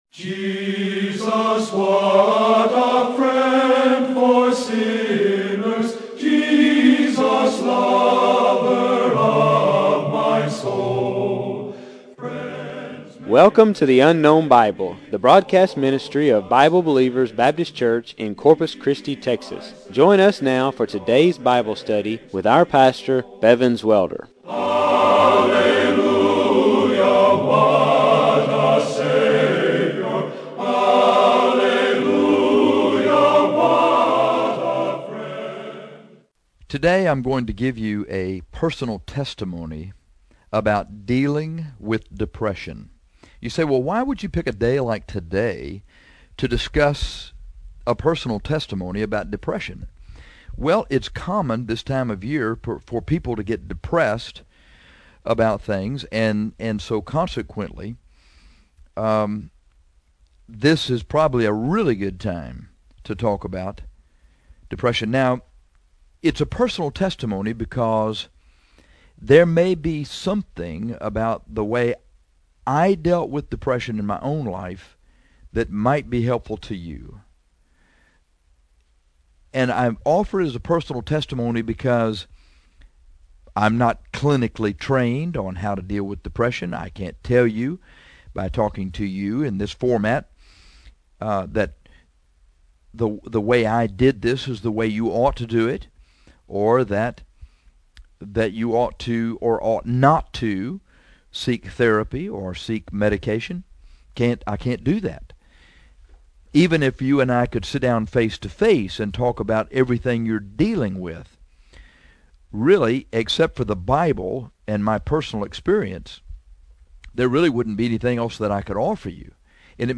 This is a personal testimony about dealing with depression – I offer it because there may be something about the way that I dealt with depression in my own life that might be helpful to you – by no means am I suggesting that the way I had to deal with depression is the way that you will have to deal with it.